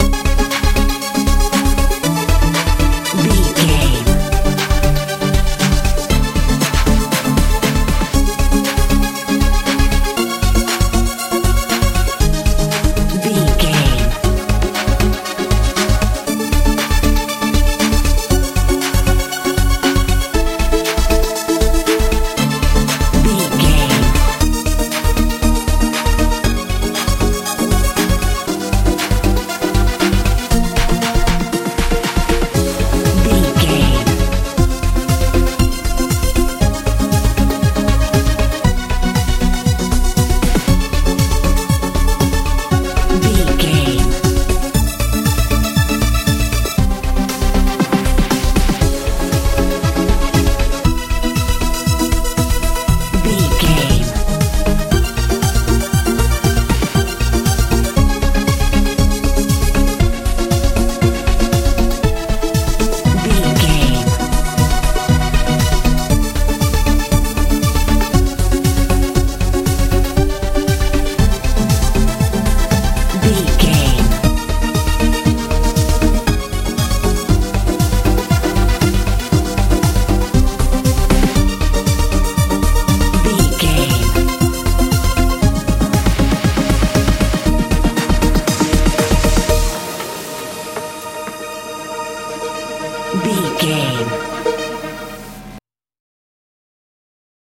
techno feel
Ionian/Major
A♭
groovy
energetic
synthesiser
bass guitar
drums
80s
90s
strange
high tech